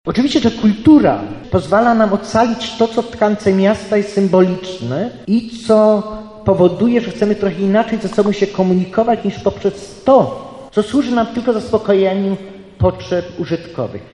Na ten temat dyskutowali przedstawiciele instytucji kultury z różnych stron Polski podczas debaty Kultura samorządowa 25+